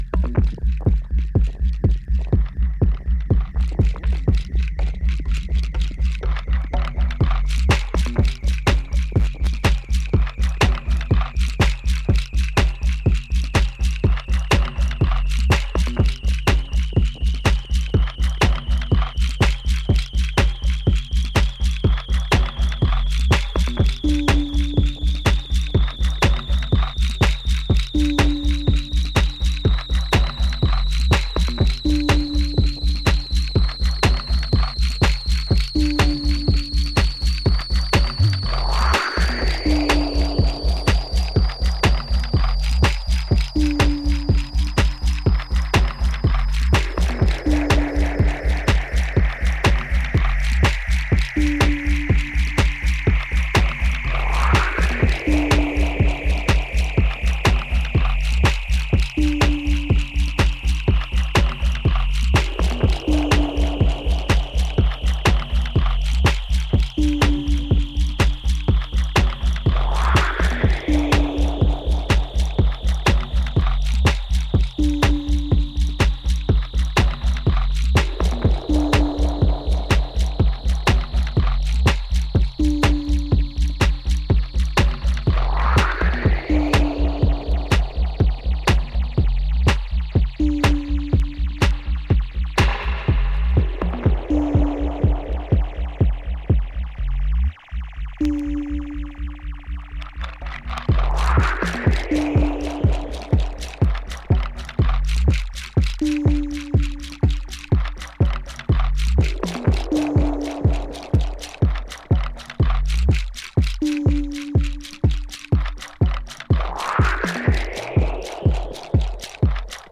ユーモラス、かつ黒い狂気が充満している傑作です。